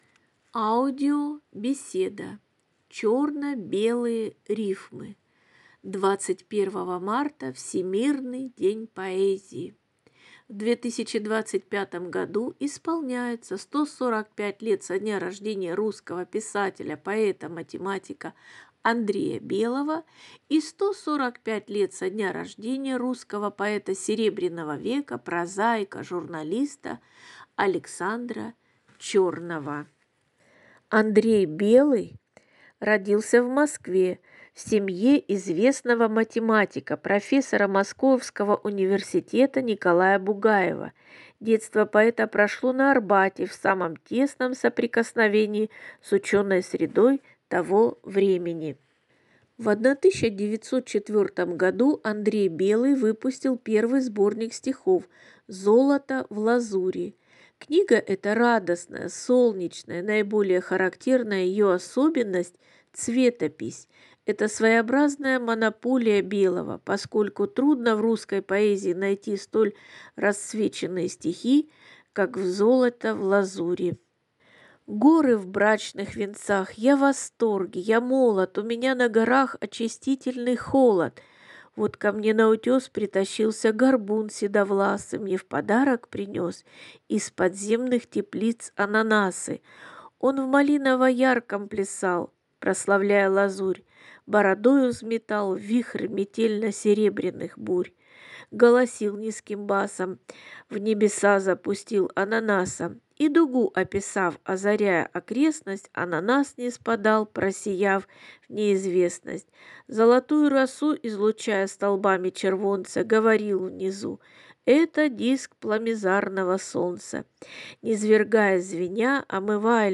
В беседе прозвучат стихотворения «На горах» и «Вешалка дураков».